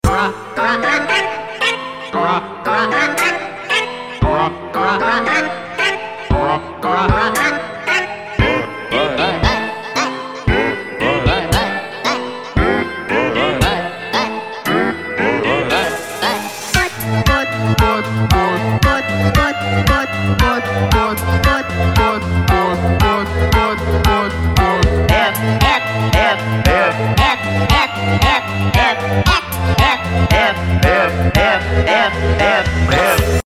Remix (Battle Cover)